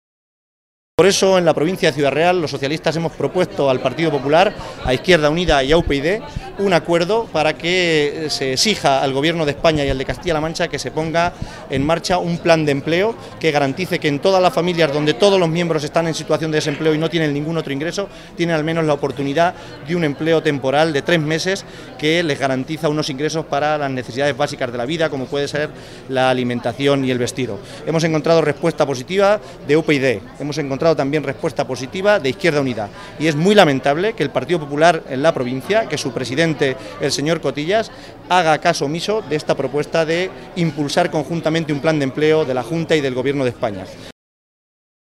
AUDIO_CABALLERO-LA_SOLANA.mp3